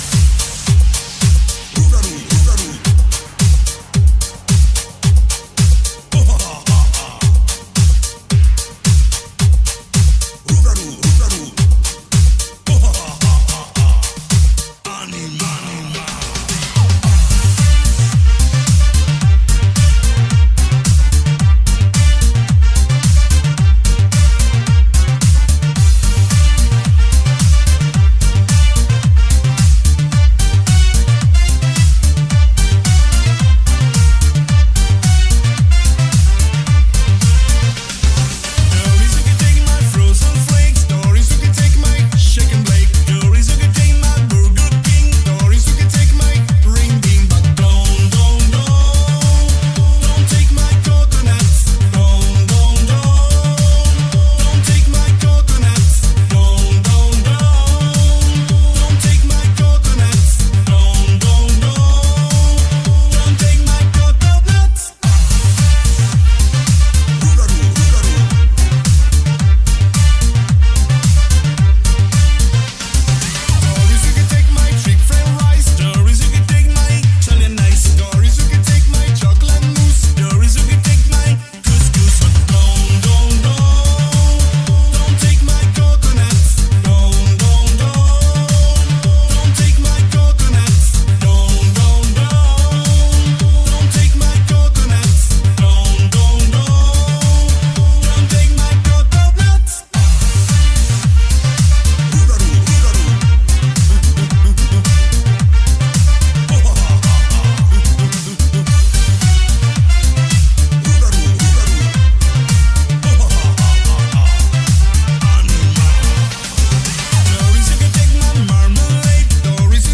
Italo_Disco_Bass.mp3